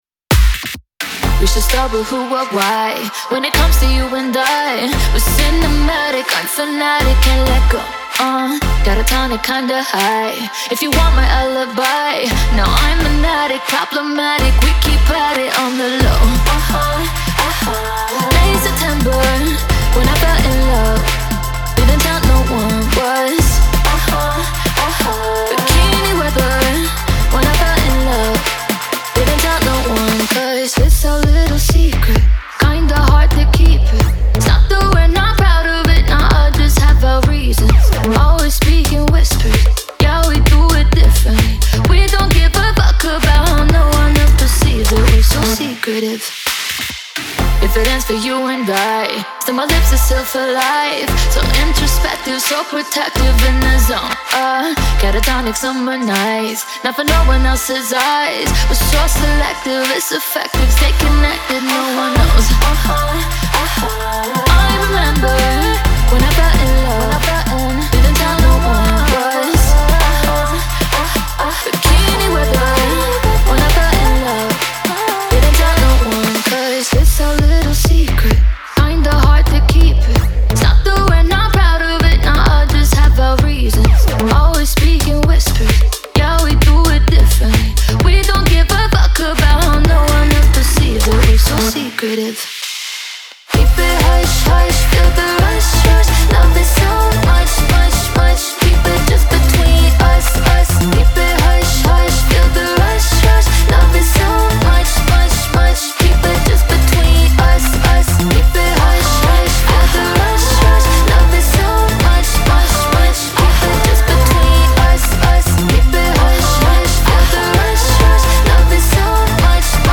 BPM130-130
Audio QualityPerfect (High Quality)
Hyperpop song for StepMania, ITGmania, Project Outfox
Full Length Song (not arcade length cut)